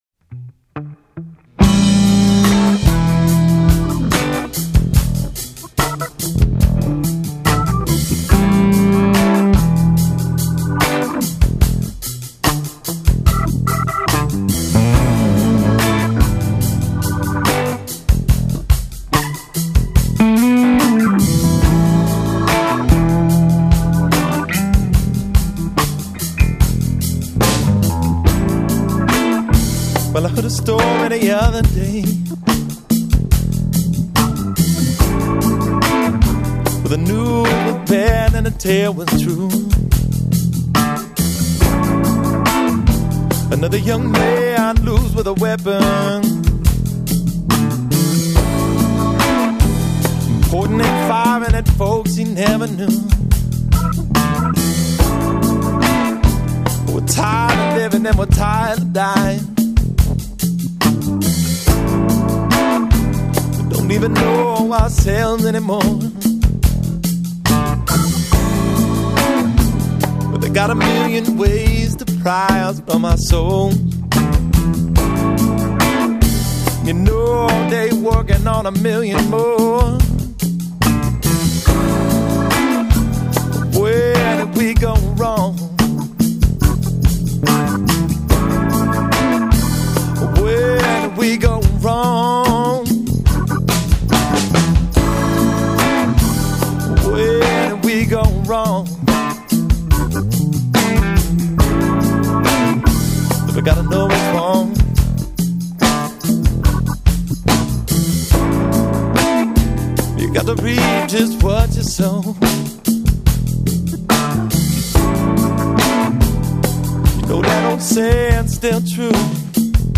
Recorded in New Orleans, LA.